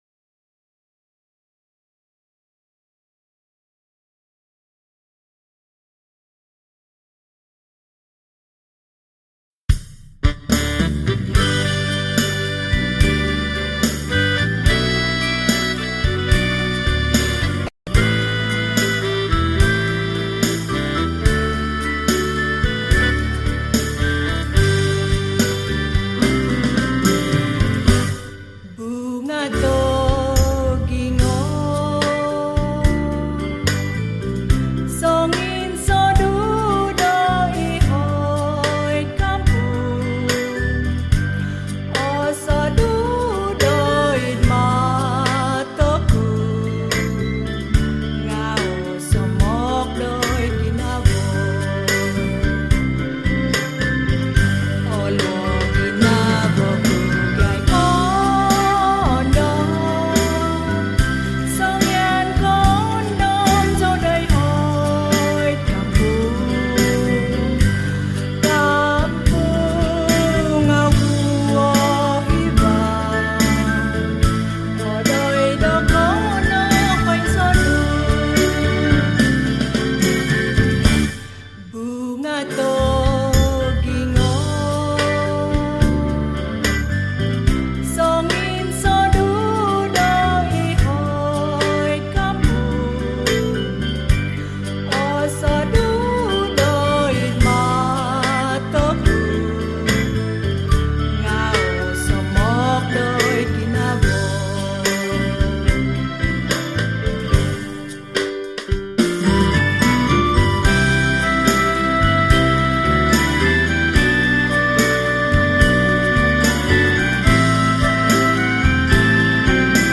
Kadazan Evergreen Song